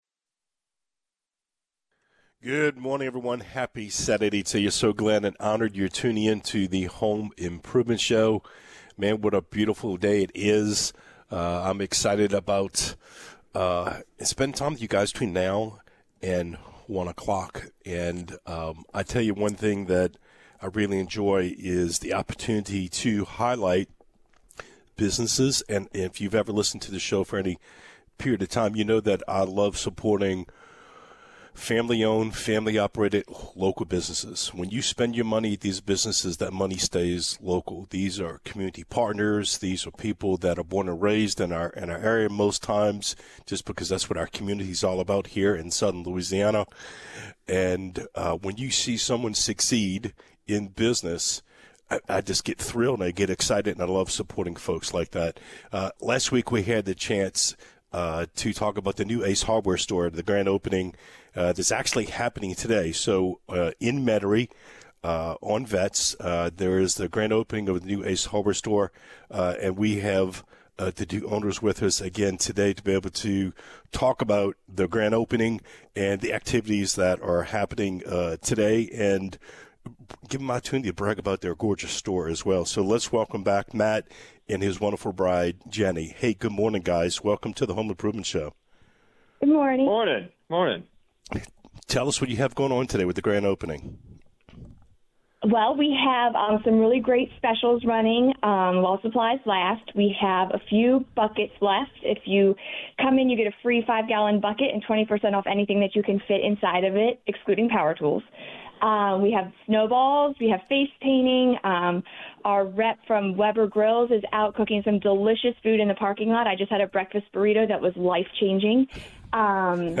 Listen to our feature on the Home Improvement Show